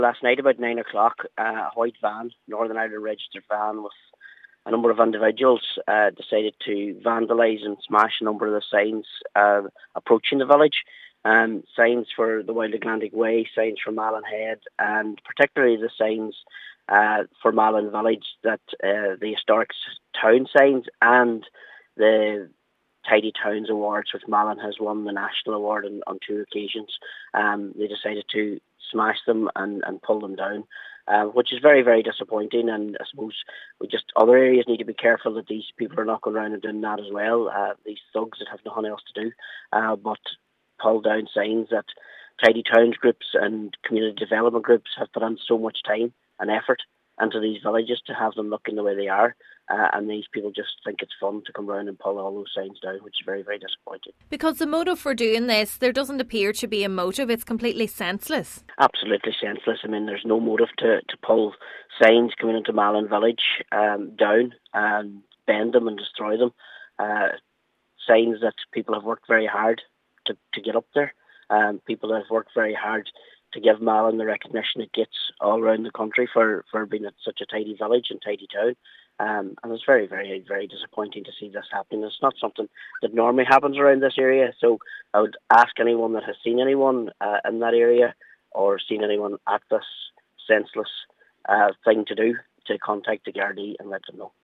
Councillor Martin McDermott is urging other villages to be on alert.